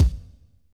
Kick (66).wav